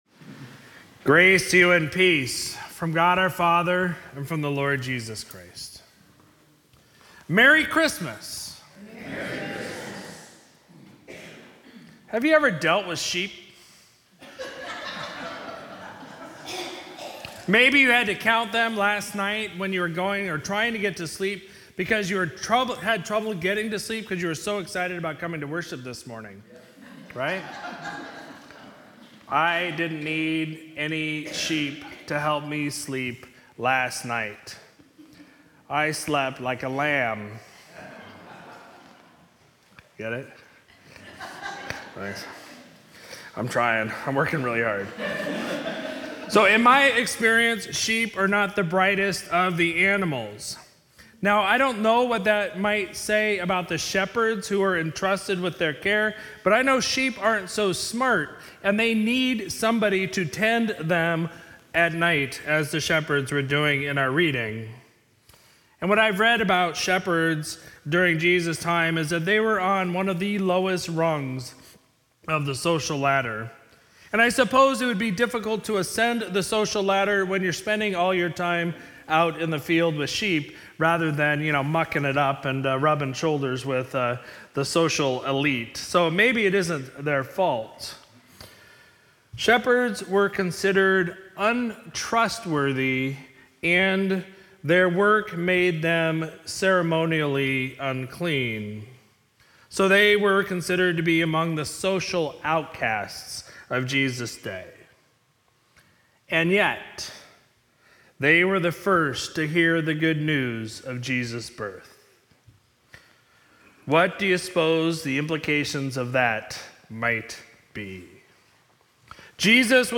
Sermon for Sunday, December 25, 2022